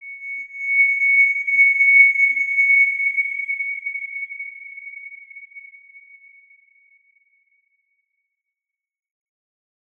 X_Windwistle-C#6-mf.wav